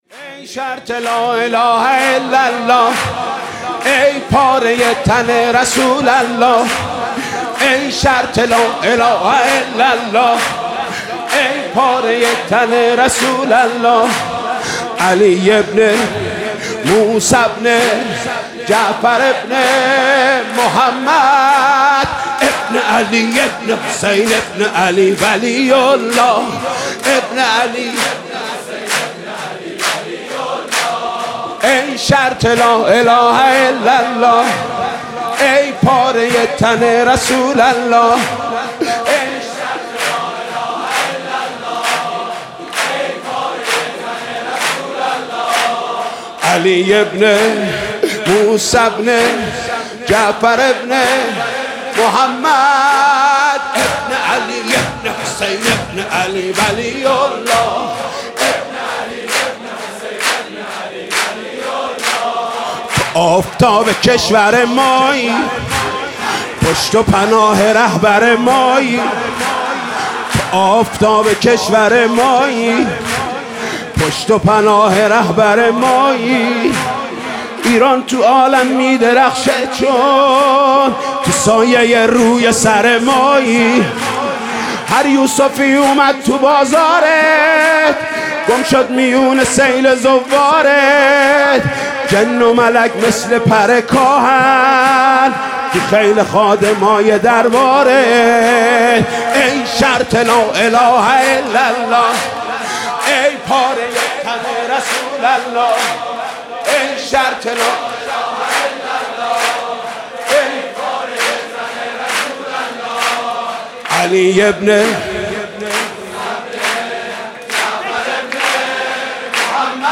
«میلاد امام رضا 1396» سرود: ای شرط لا اله الا الله